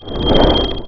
1 channel
ui_createObject.wav